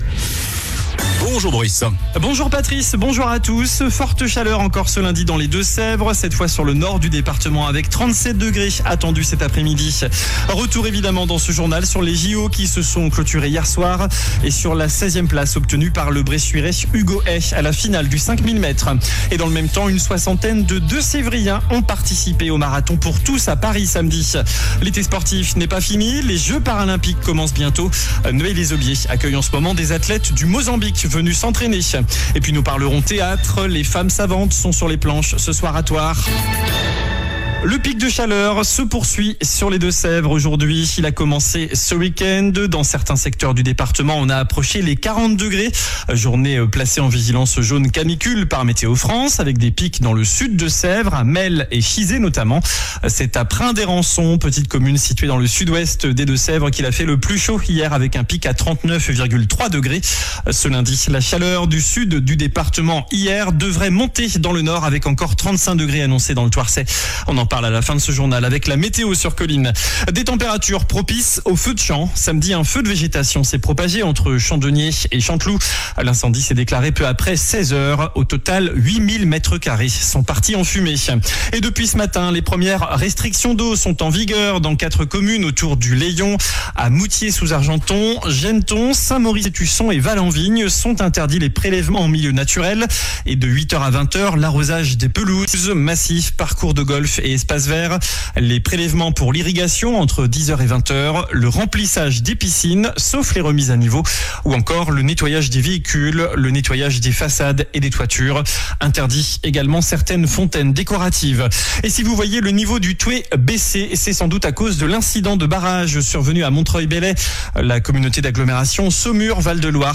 JOURNAL DU LUNDI 12 AOÛT ( MIDI )